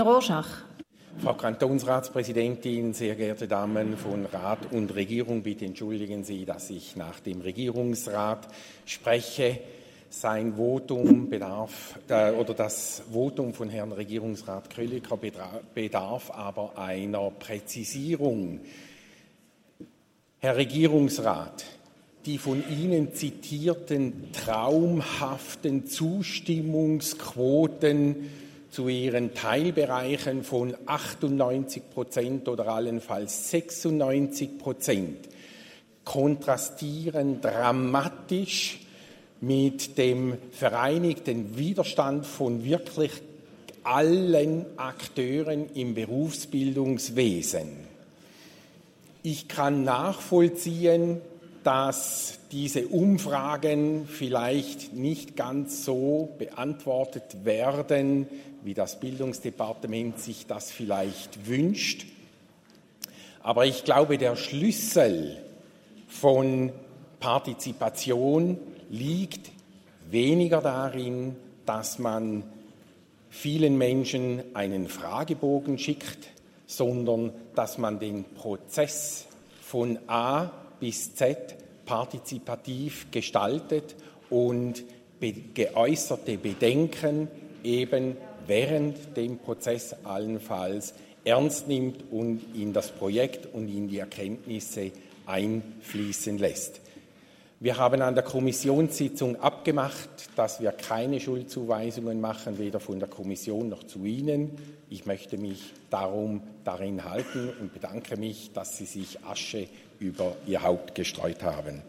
Session des Kantonsrates vom 18. bis 20. September 2023, Herbstsession